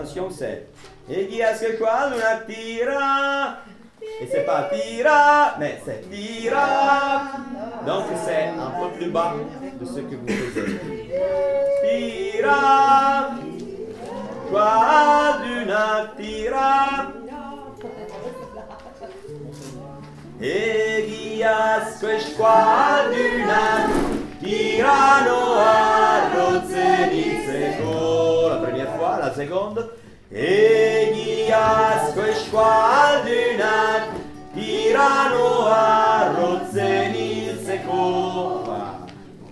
femmes_part3_attention.mp3